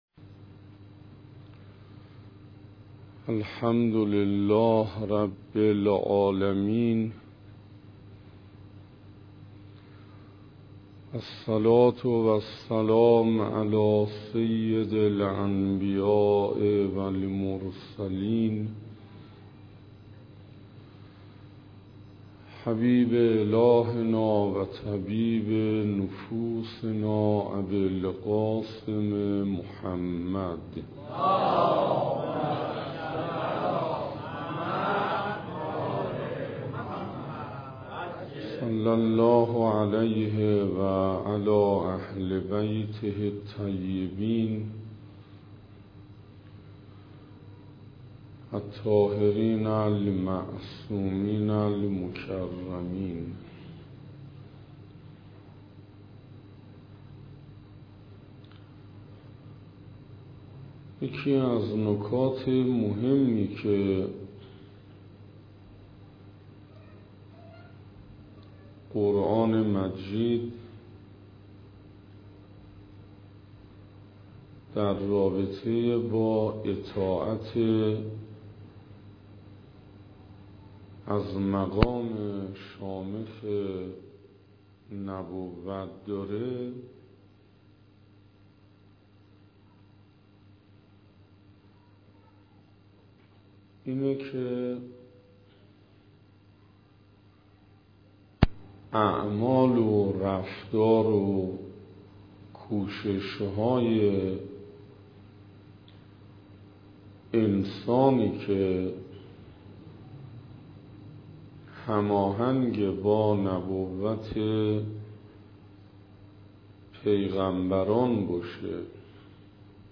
سخنراني دوازدهم